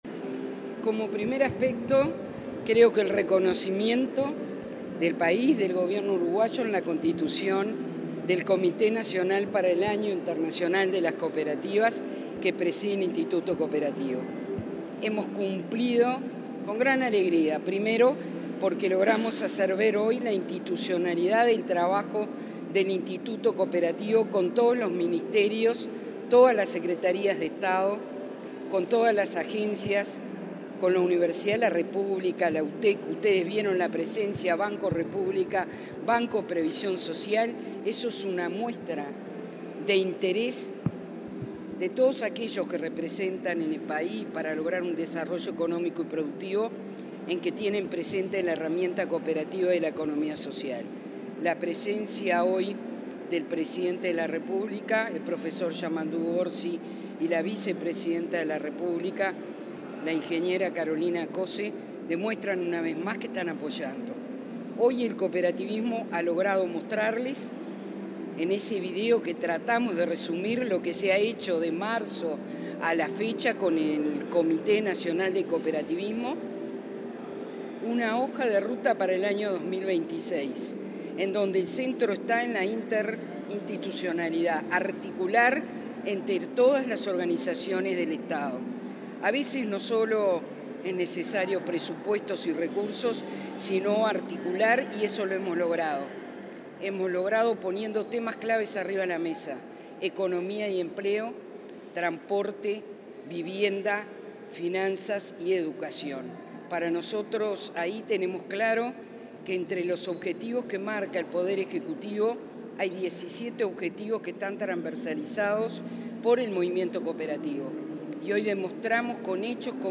En una rueda de prensa, la presidenta de Inacoop, Graciela Fernández, se refirió a las principales líneas de acción para impulsar a las cooperativas.